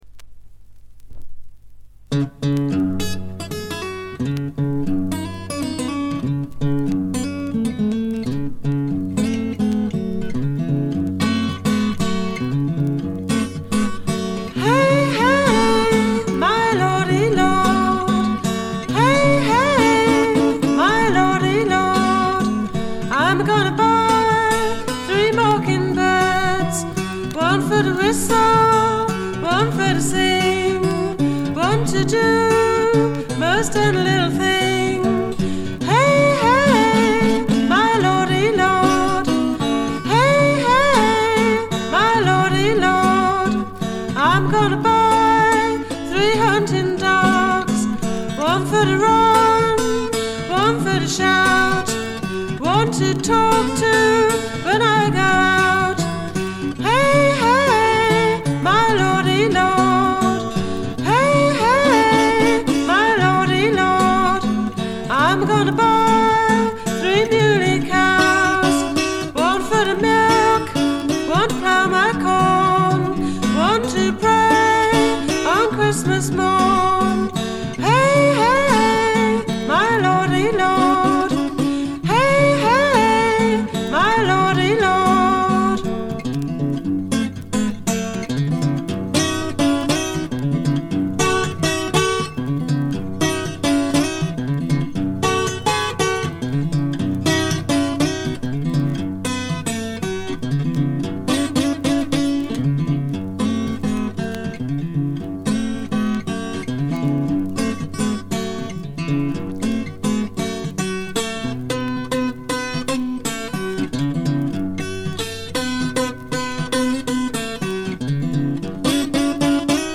バックグラウンドノイズ、軽微なチリプチが聞かれはしますがほとんど気にならないレベルと思います。
試聴曲は現品からの取り込み音源です。